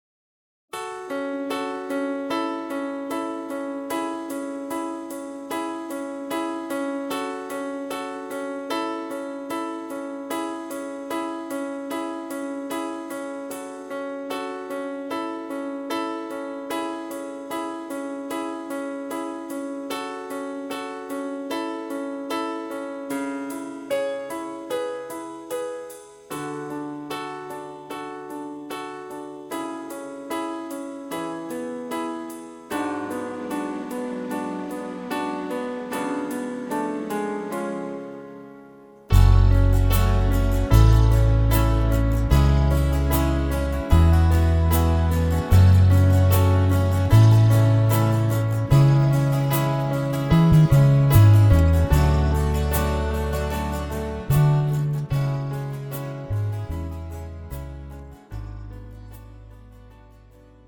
음정 (-1키)
장르 뮤지컬 구분